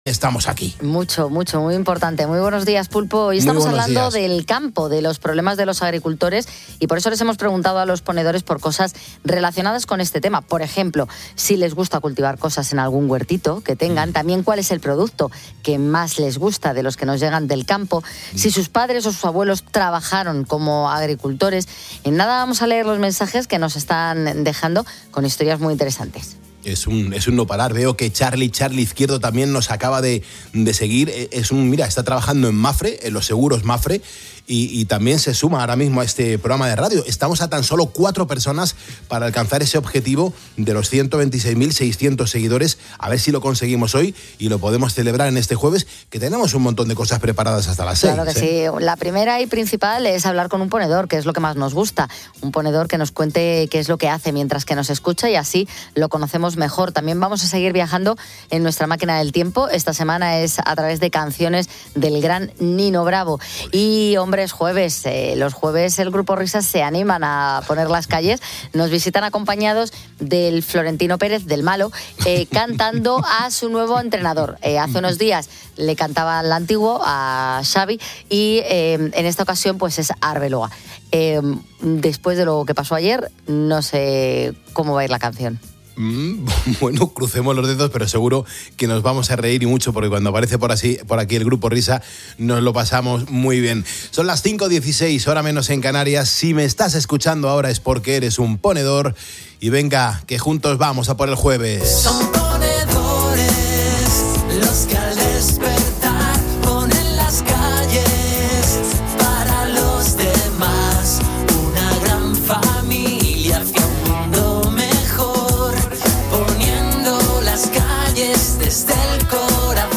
En conversación